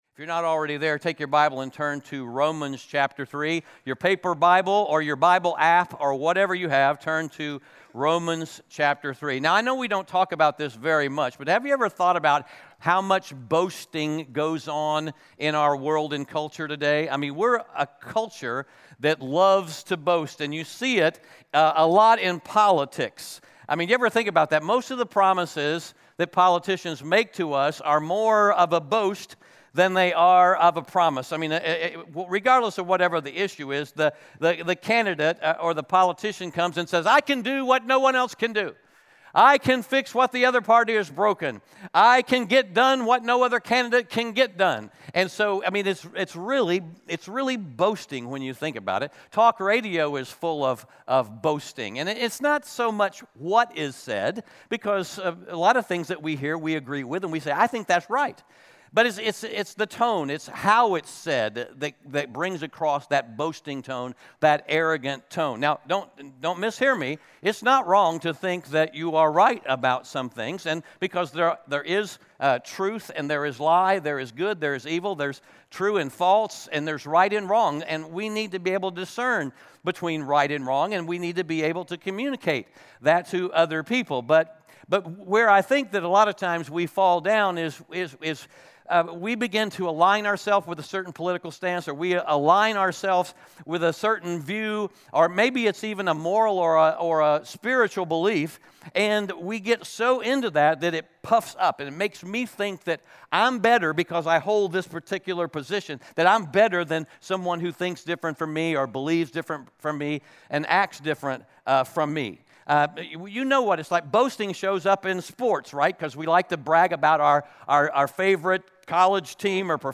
Romans 3:21-31 Audio Sermon Notes (PDF) Onscreen Notes Ask a Question *We are a church located in Greenville, South Carolina.